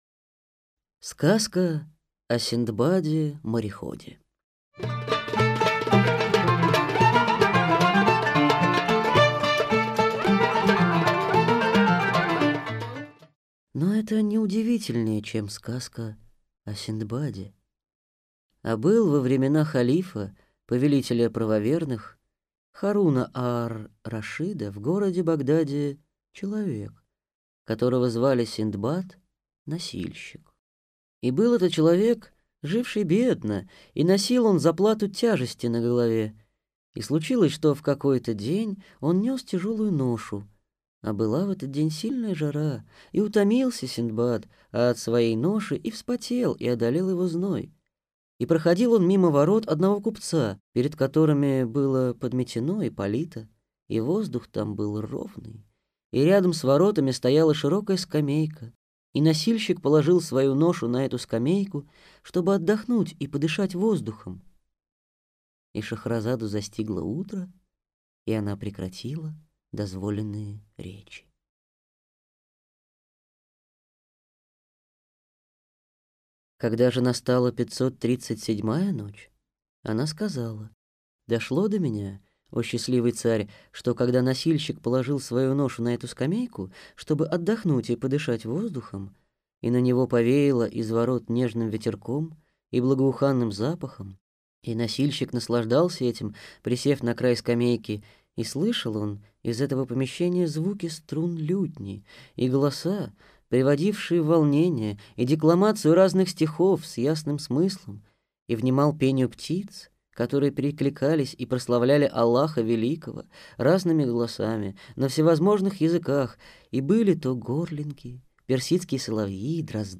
Аудиокнига Арабские сказки 1001 ночи | Библиотека аудиокниг